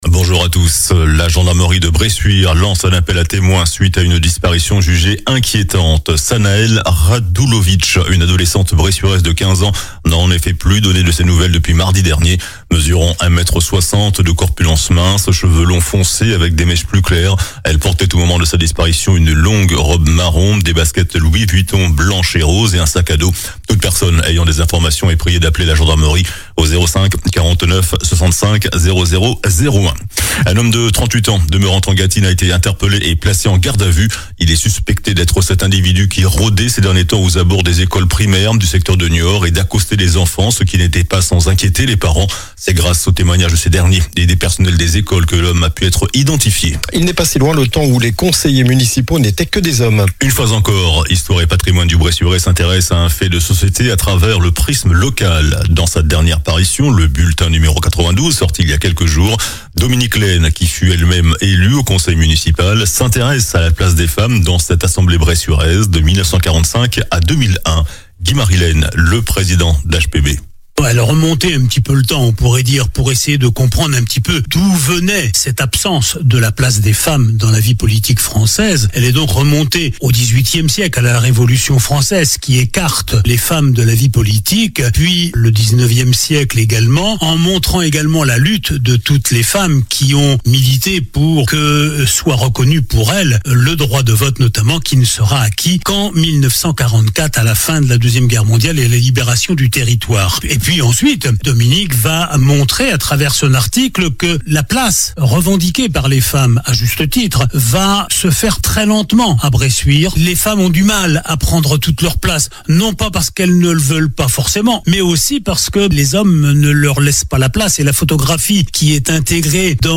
Journal du samedi 28 juin